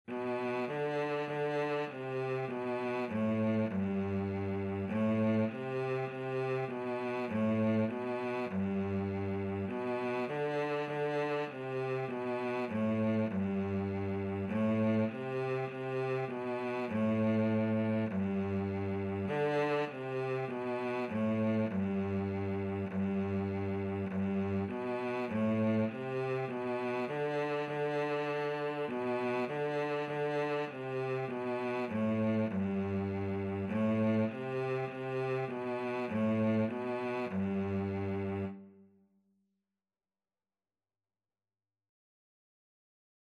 4/4 (View more 4/4 Music)
G3-D4
Classical (View more Classical Cello Music)